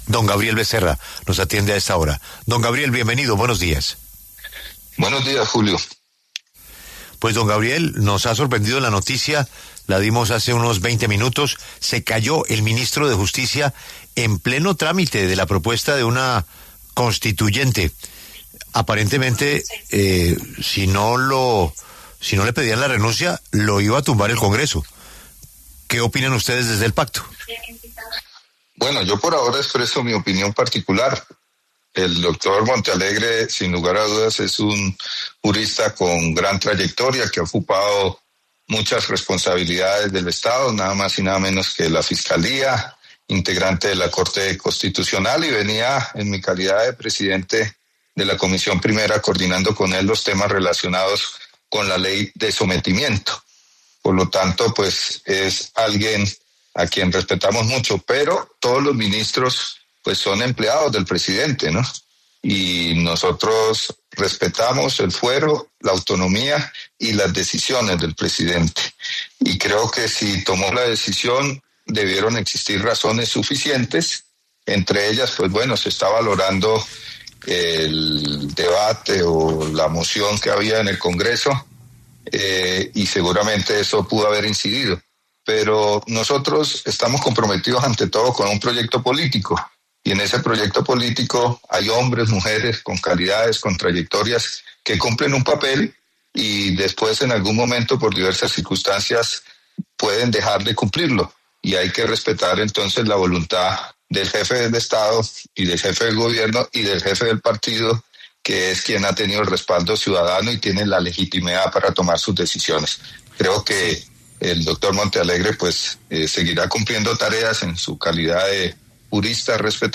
Gabriel Becerra, representante a la Cámara, pasó por los micrófonos de La W, con Julio Sánchez Cristo, para hablar de la salida del ministro de Justicia, Eduardo Montealegre, luego de que La W revelará en primicia que el presidente de la República, Gustavo Petro, le pidió la carta de renuncia.